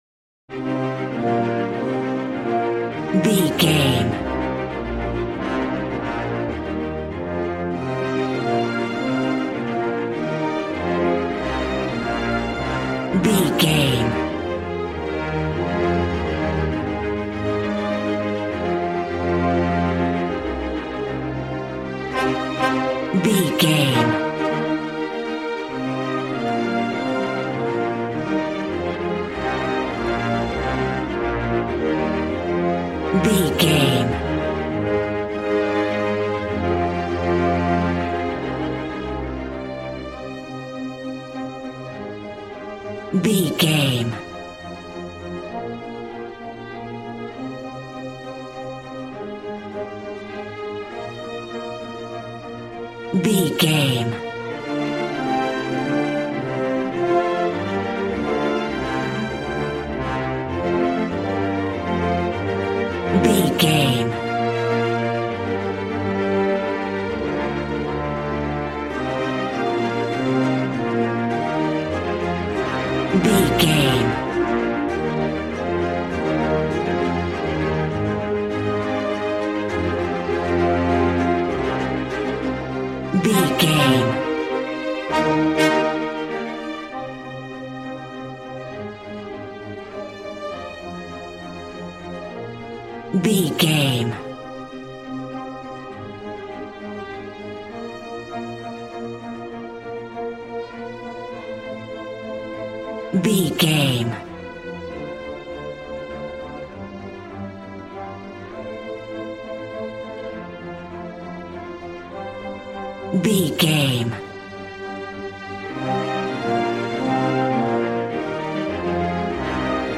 A classical music mood from the orchestra.
Regal and romantic, a classy piece of classical music.
Aeolian/Minor
regal
cello
violin
strings